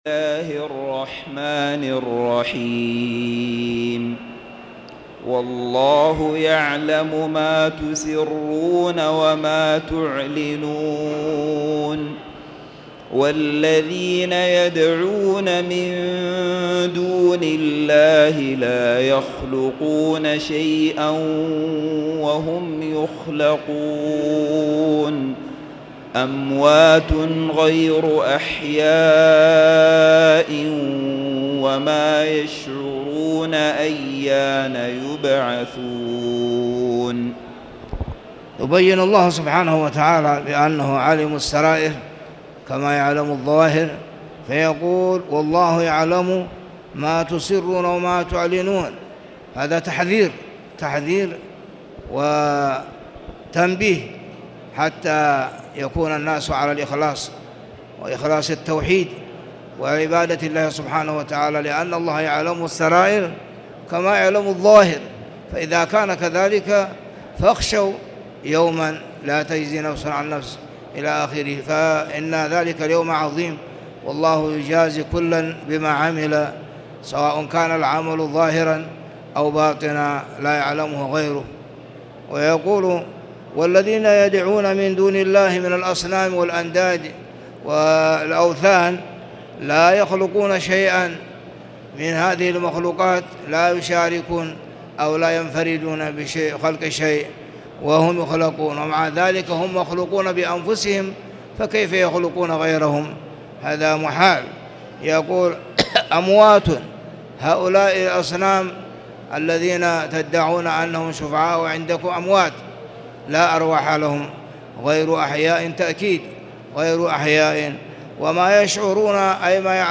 تاريخ النشر ٢٦ محرم ١٤٣٨ هـ المكان: المسجد الحرام الشيخ